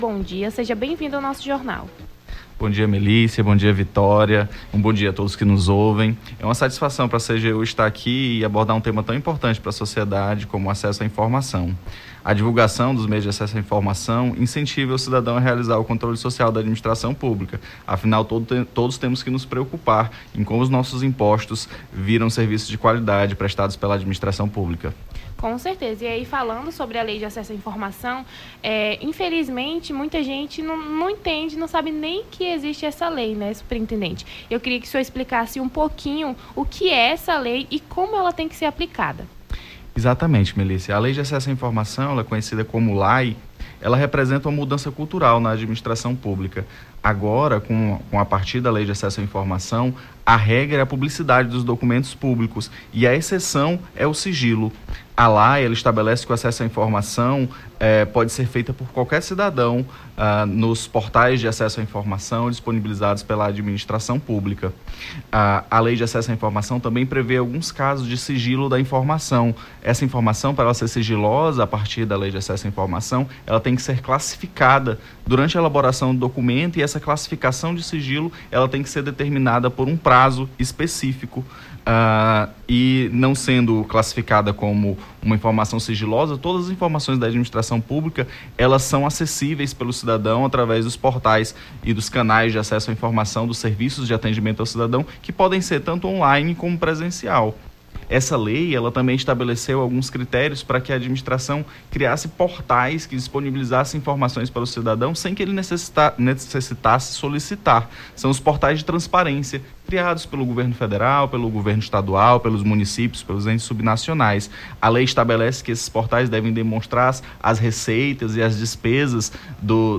Nome do Artista - CENSURA - ENTREVISTA (CGU) 19-10-23.mp3